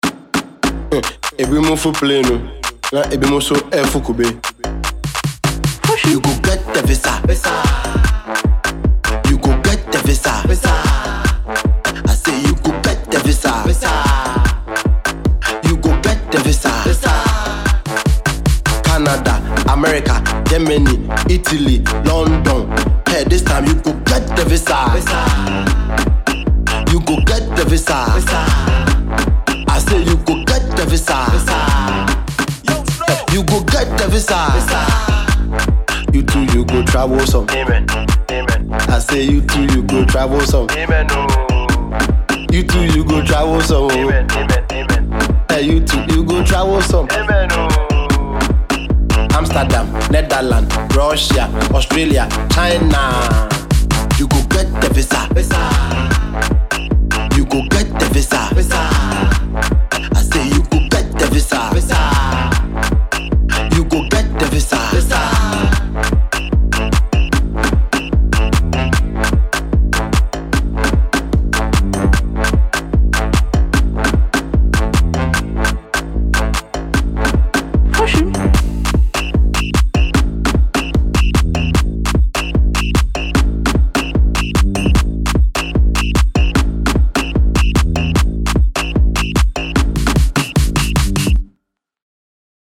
Enjoy this danceable song.